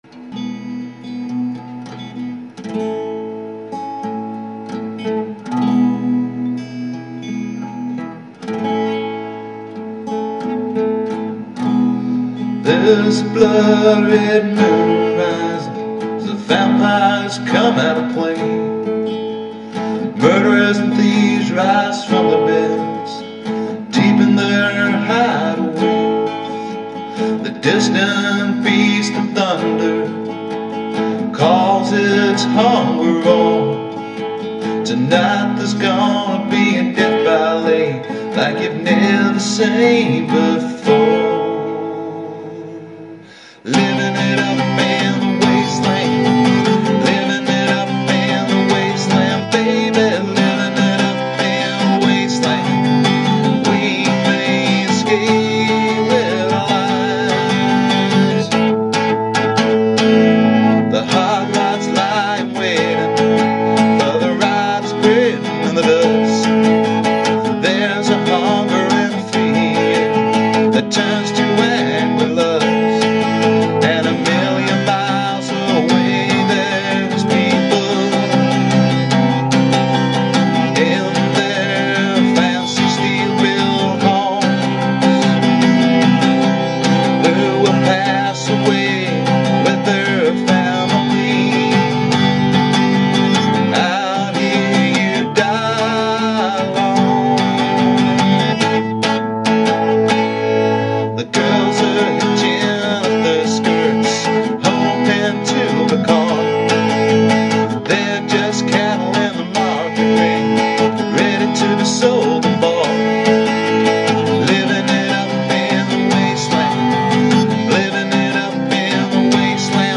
Country
Folk